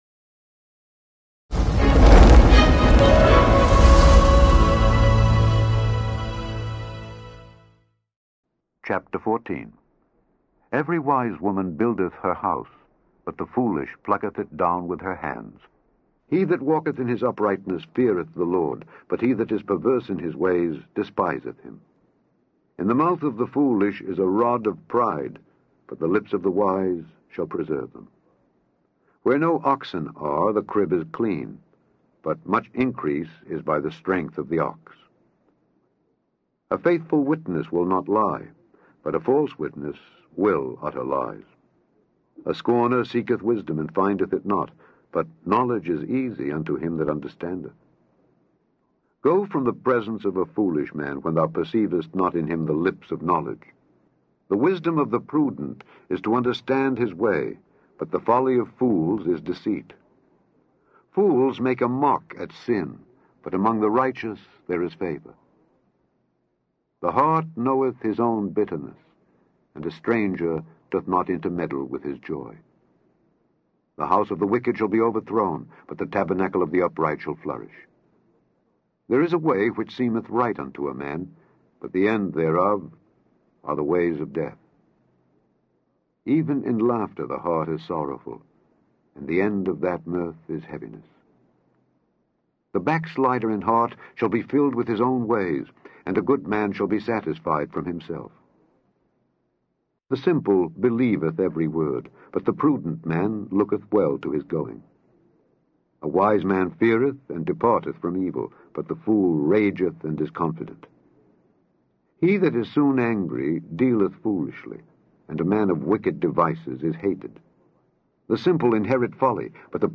Daily Bible Reading: Proverbs 14-15
Click on the podcast to hear Alexander Scourby read Proverbs 14-15.